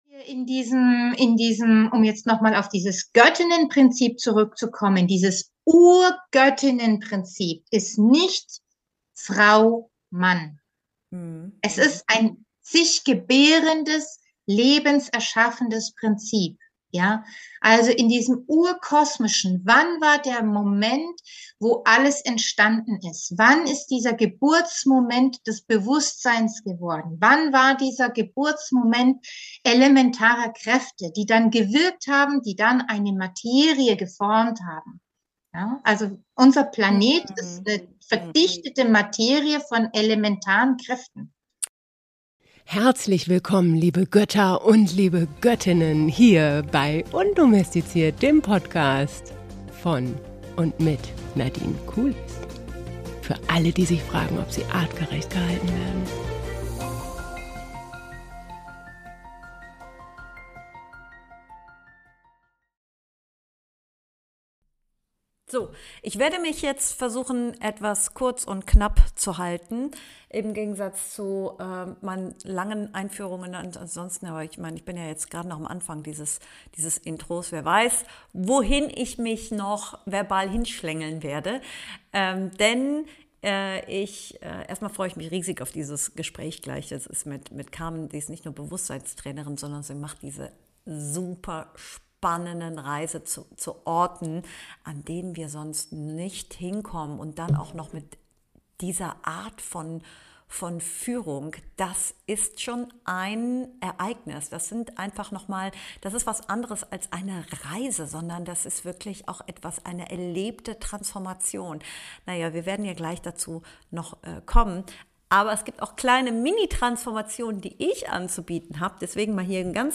#60 Artemision, das vergessene Weltwunder – Warum ihre Kraft heute wichtig ist. Im Gespräch